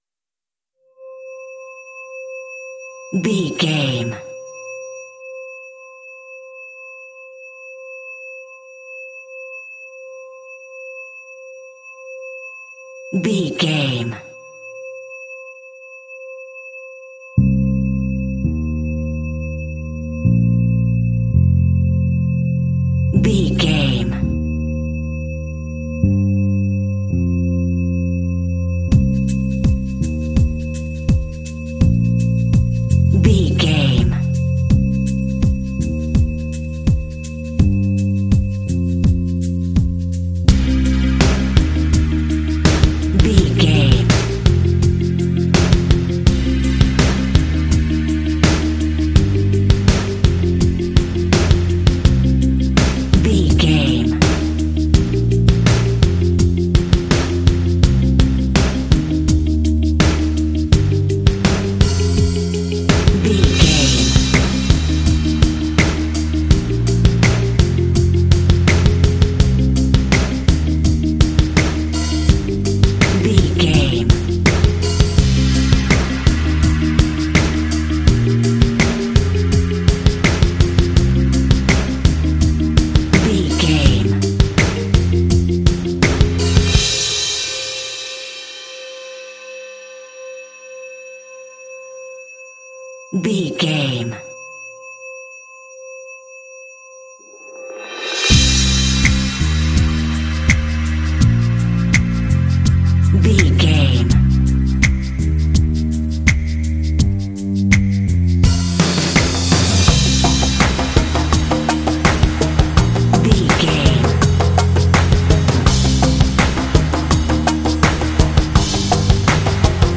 Epic / Action
Aeolian/Minor
groovy
powerful
epic
inspirational
synthesiser
bass guitar
percussion
drums
electric guitar
symphonic rock
cinematic
classical crossover